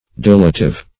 deletive - definition of deletive - synonyms, pronunciation, spelling from Free Dictionary
Deletive \Del"e*tive\, a. Adapted to destroy or obliterate.